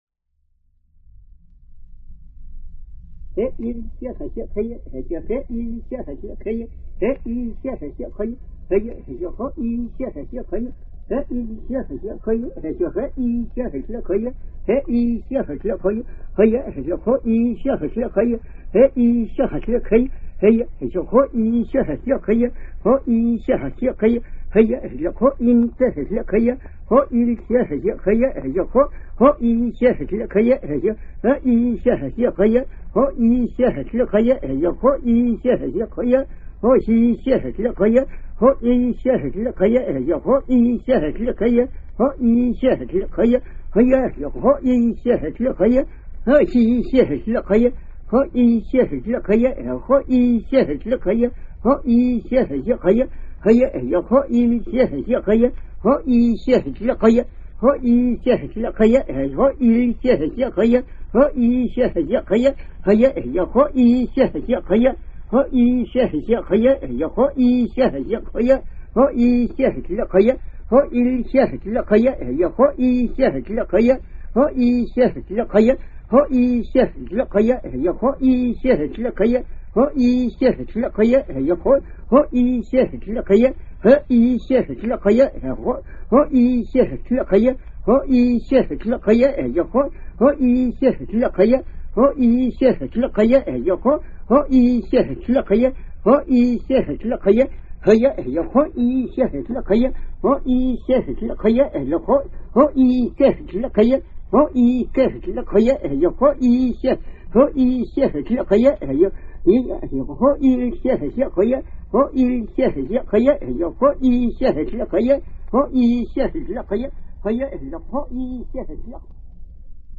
Selk'nam (Ona) chants of Tierra del Fuego
41 chants of the hain ceremony